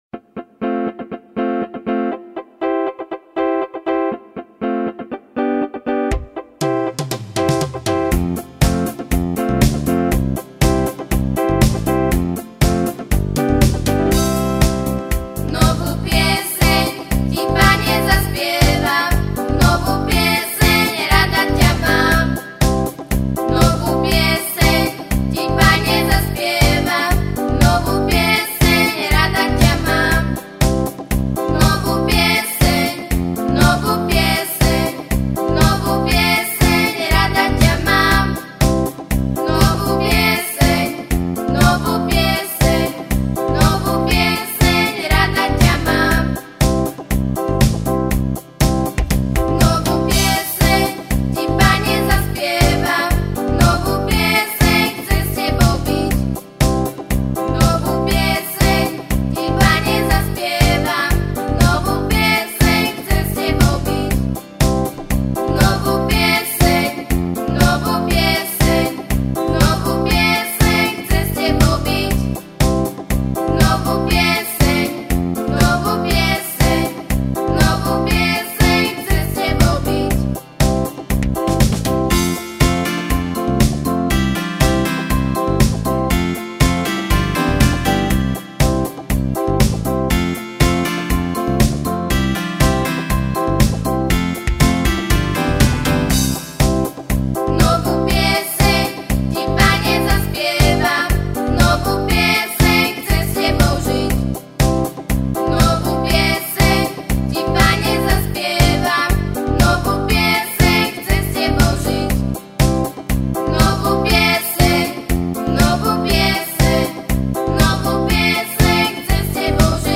Křesťanské písně